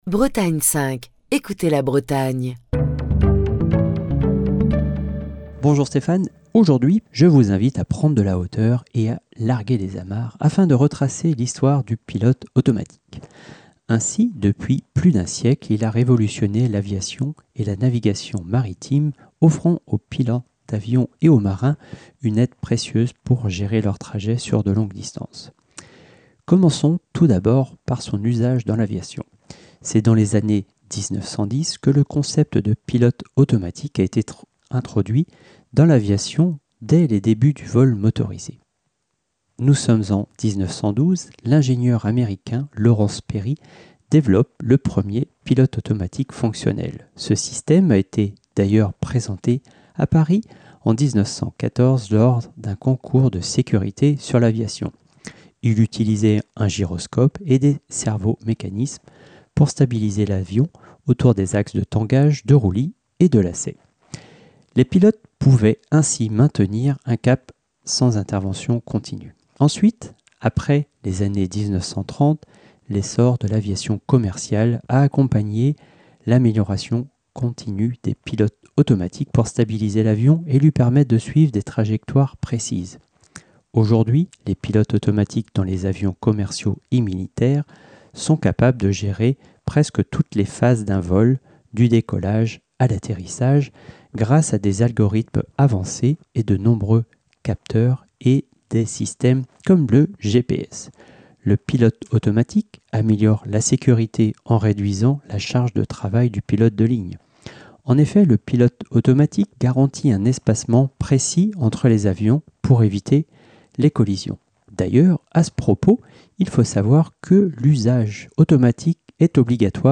Chronique du 4 décembre 2024.